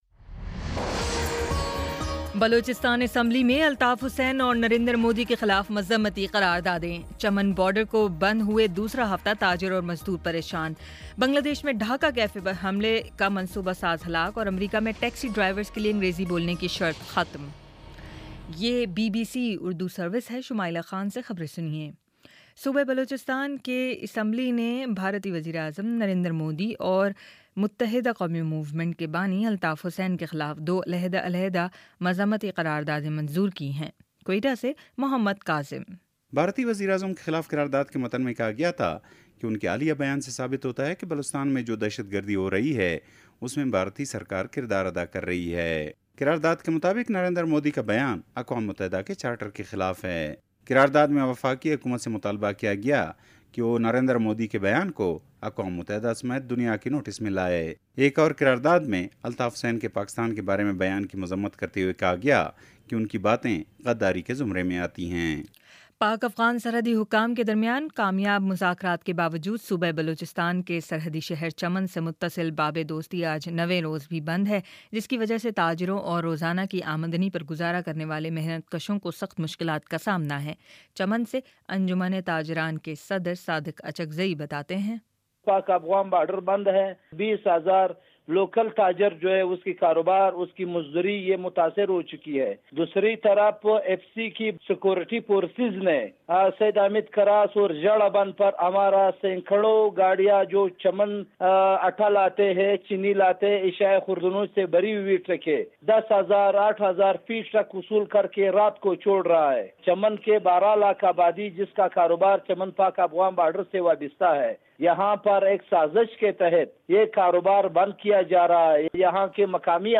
اگست 27 : شام چھ بجے کا نیوز بُلیٹن